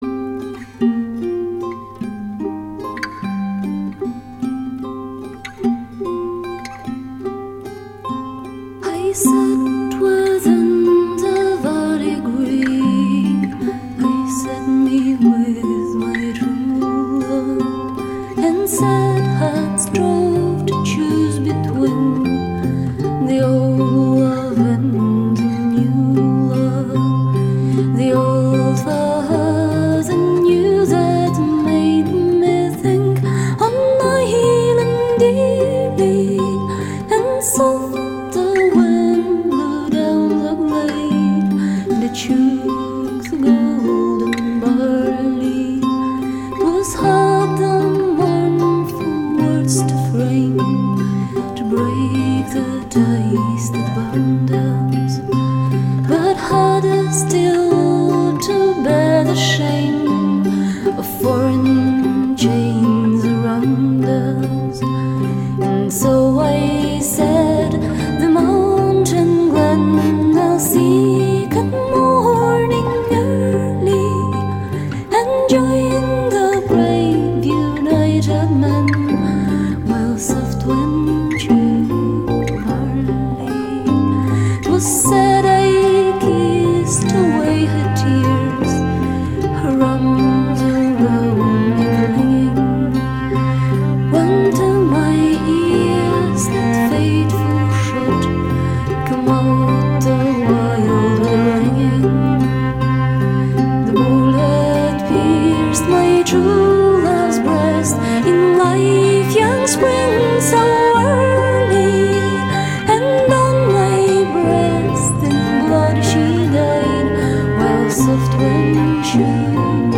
幻想とメランコリーの世界へと誘うフェアリーフォーク
終始優しく、ノスタルジーなサウンドに心癒されることは言うまでもありません。
vocal, fluer,koval,bagpipe
guitar,buzuki,portuguese guitar,mandochello, octave vandolin
percussion
violin
viola